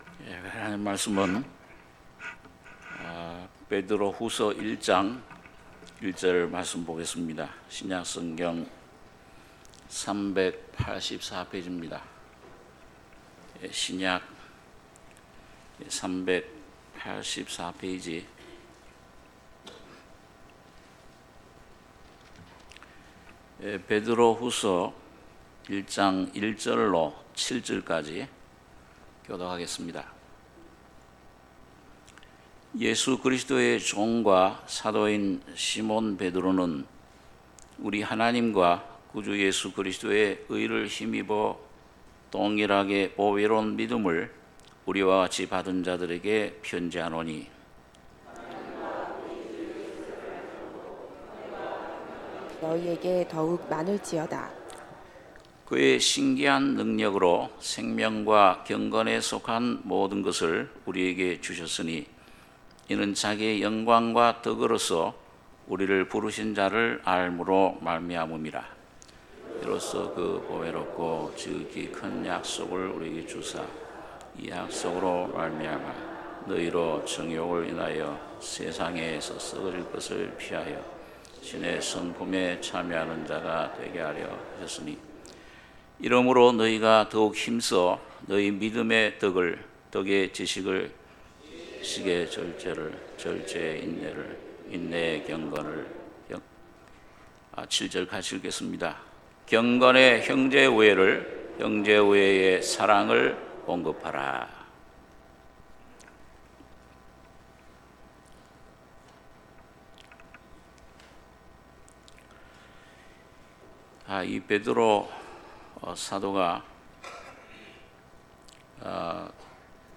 베드로후서 1장 1절~7절 주일1부 - 삼성교회